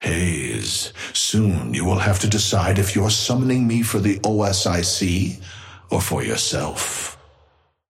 Amber Hand voice line - Haze, soon you will have to decide if you're summoning me for the OSIC or for yourself.
Patron_male_ally_haze_start_01.mp3